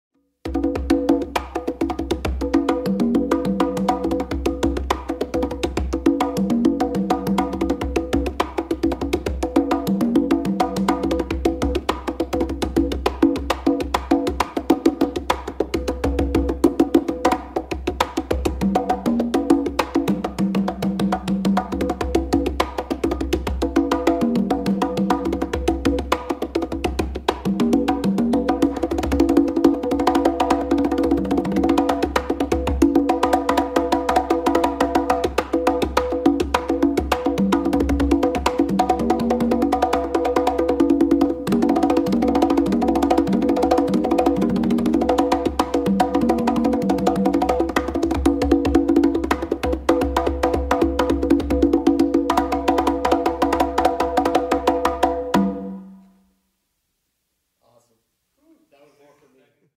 نوازنده تومبا معمولاً با استفاده از کف دست و انگشتان روی پوست ساز ضربه می زند و از تکنیک های متنوعی مانند ضربات باز، بسته، باس، تَپ و اسلپ برای ایجاد صدا های مختلف استفاده میکند.